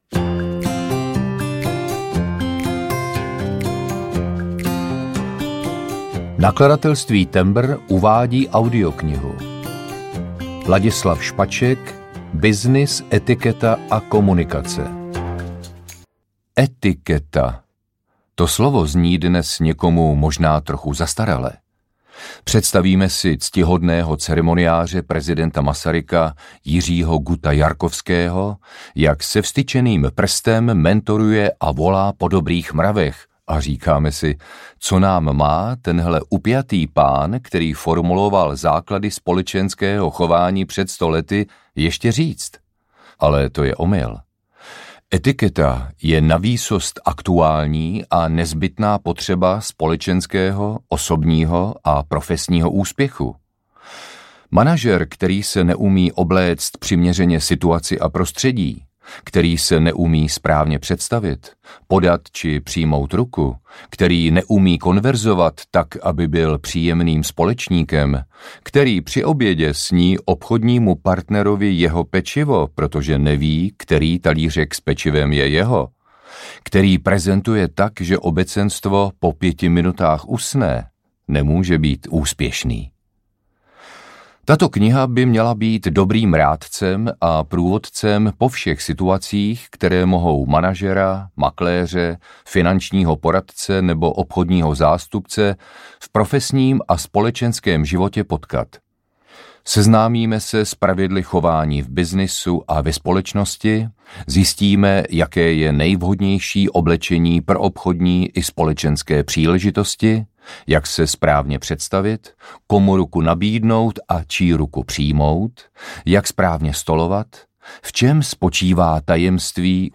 Byznys etiketa a komunikace audiokniha
Ukázka z knihy
• InterpretLadislav Špaček